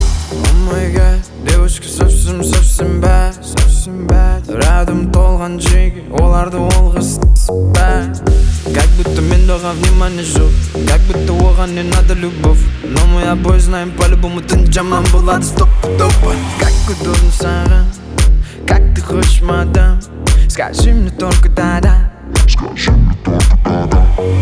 • Качество: 320, Stereo
Хип-хоп
dance
club